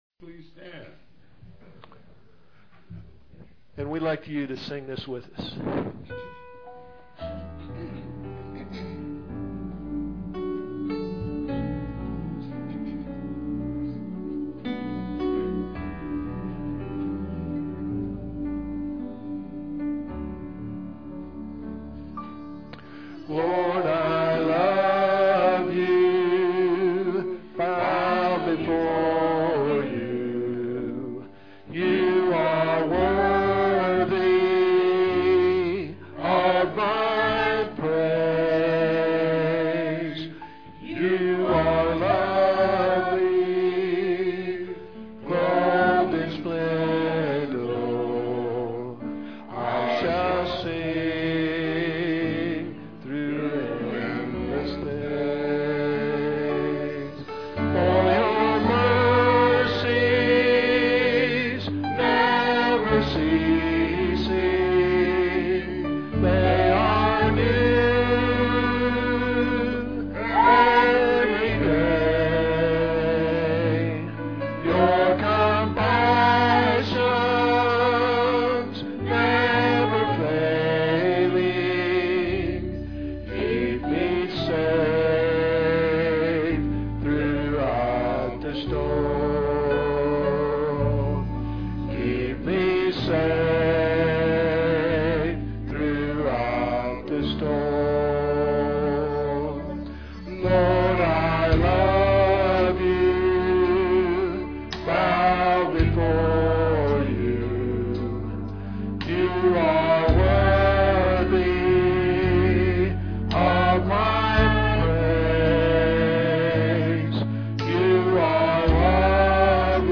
PLAY A Trust to Keep, Oct 15, 2006 Scripture: Matthew 25:14-19. Scripture Reading